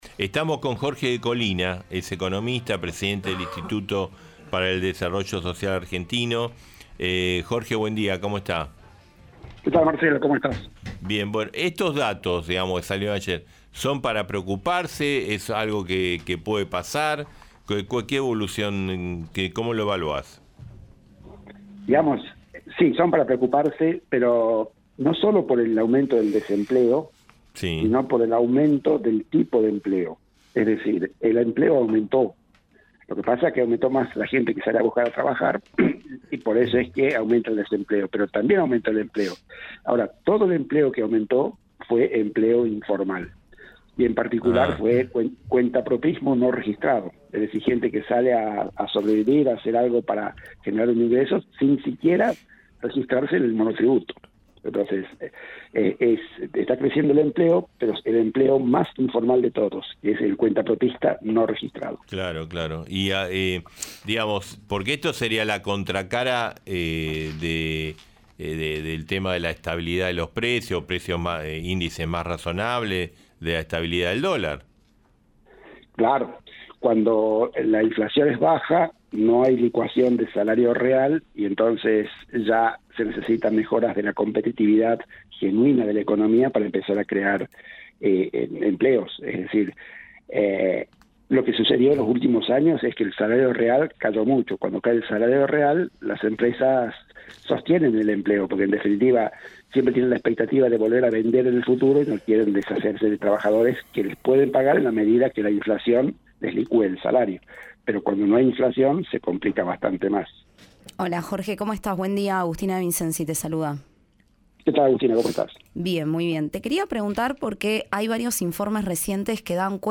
Compartimos la entrevista.